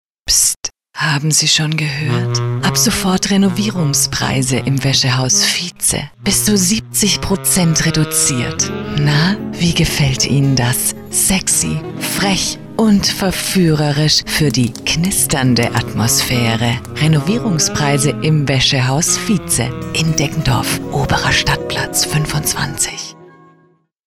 Mein großes Plus: absolut sympathische Frauenstimme, da tief & voll Ich freue mich auf Ihren Kontakt!
deutsche Sprecherin für Werbespots, Hörbücher, Emotionales, Telefonansagen.
Sprechprobe: Sonstiges (Muttersprache):
german female voice over talent